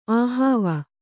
♪ āhāva